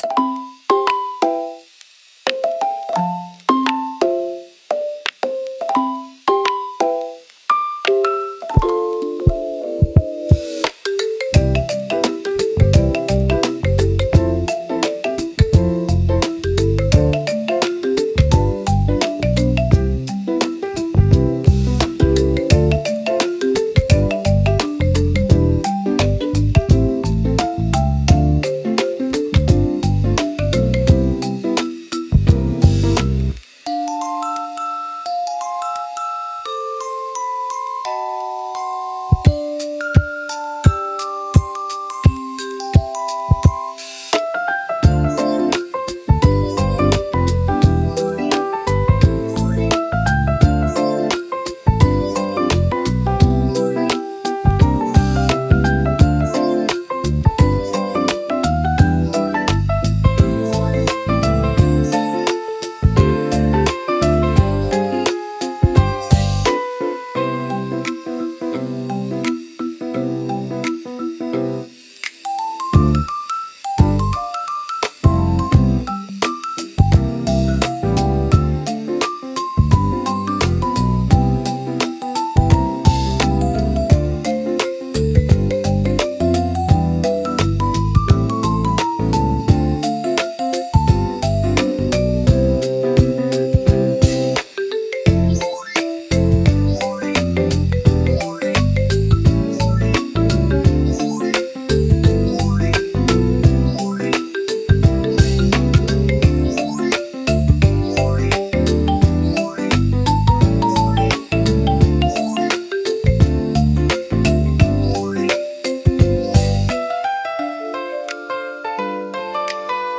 SUNO AI を使用して制作しています
まったり癒しのBGM